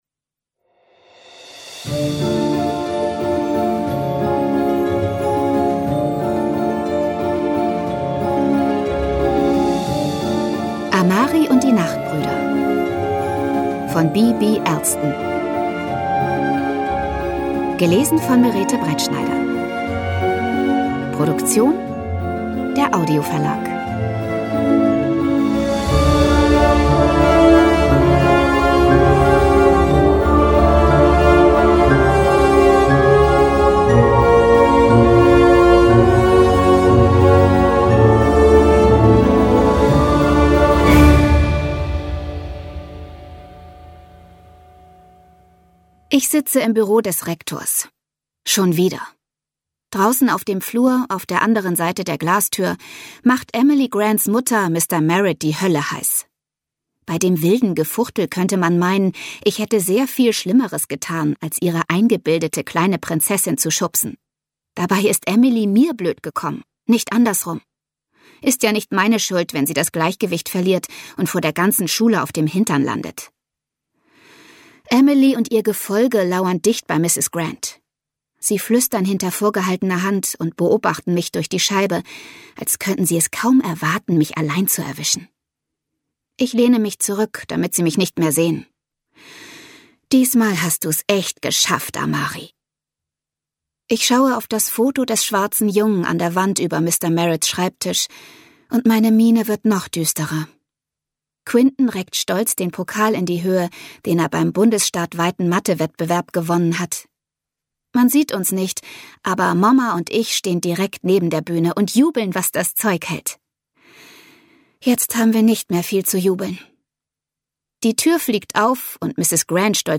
Amari und die Nachtbrüder (Teil 1) Ungekürzte Lesung mit Musik